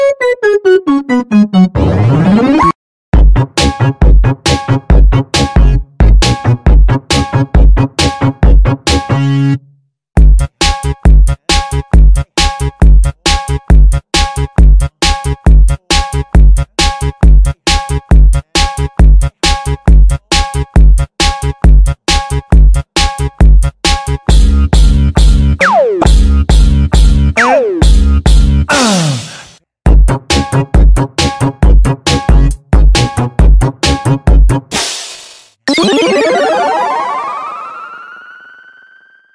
• Качество: 320, Stereo
ритмичные
веселые
без слов
басы
инструментальные
electro
японские